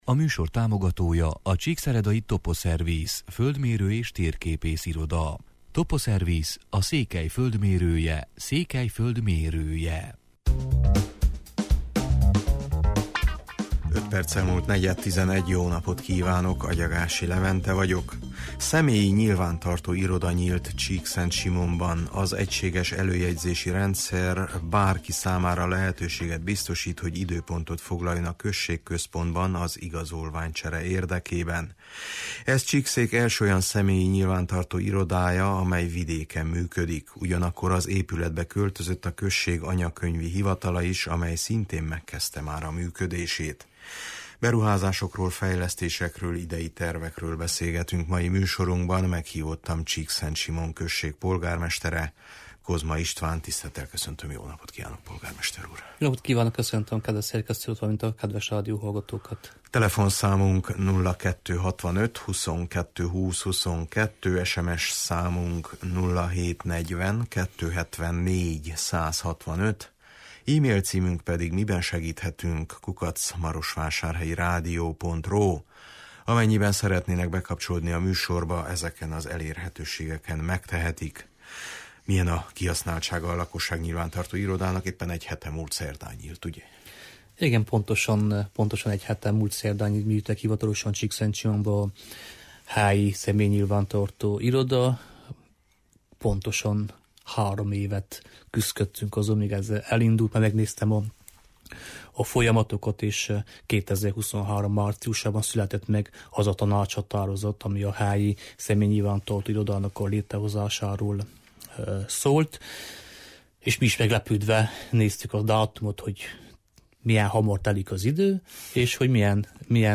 Beruházásokról, fejlesztésekről, idei tervekről beszélgetünk mai műsorunkban. Meghívottam Csíkszentsimon község polgármestere, Kozma István: